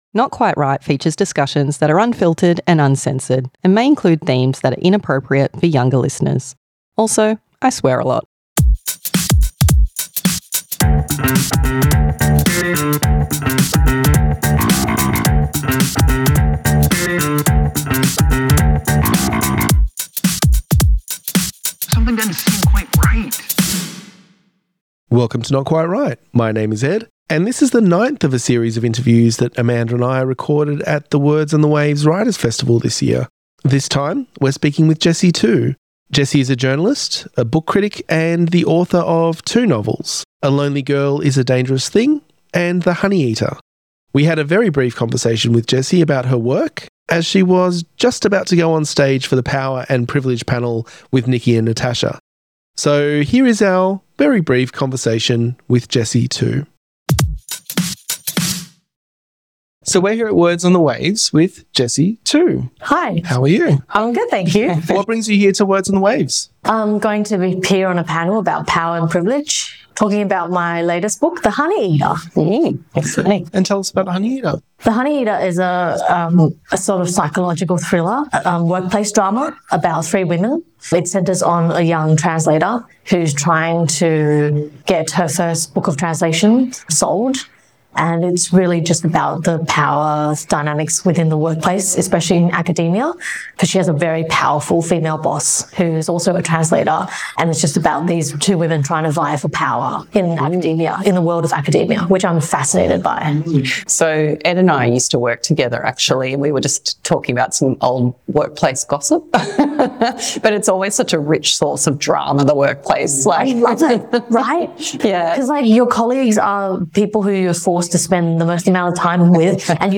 Not Quite Write Interviews